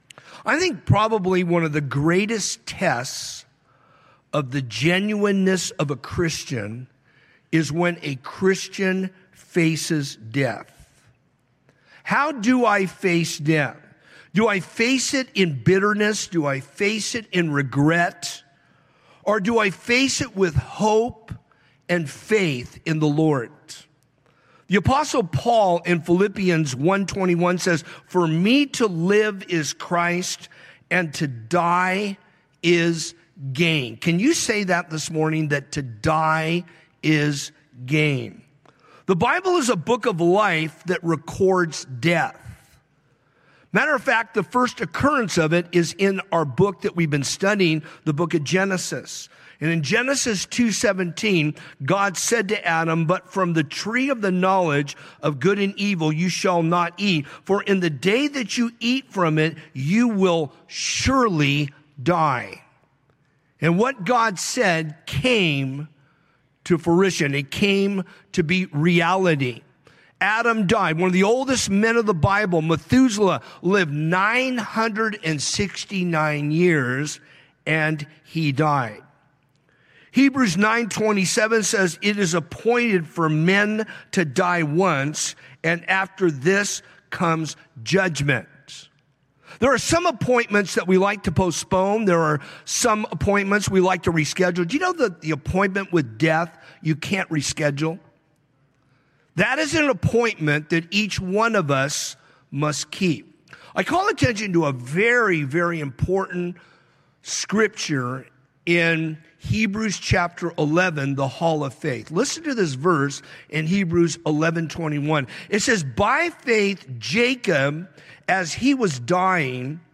From Series: "Sunday Morning - 10:30"